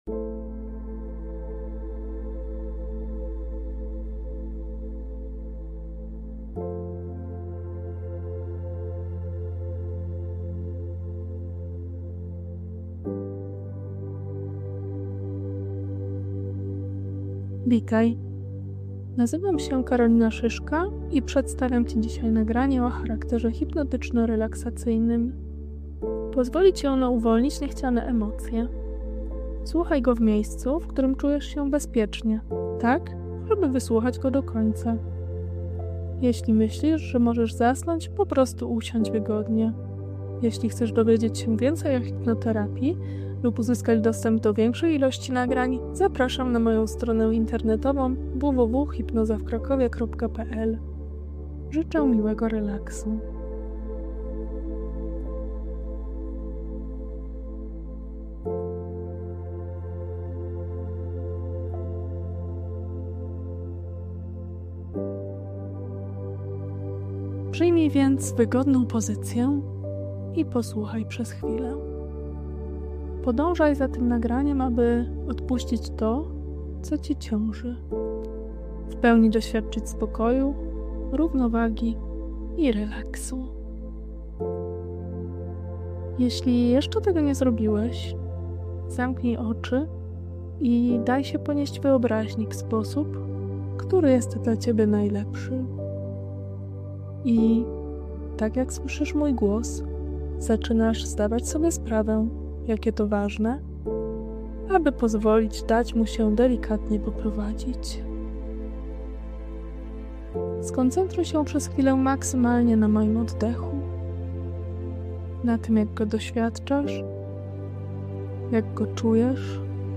Kliknij tutaj aby pobrać bezpłatne nagranie hipnotyczno - relaksacyjne
Relaks-na-plazy-z-uwolnieniem-emocji-online-audio-convertercom-ml52atof.mp3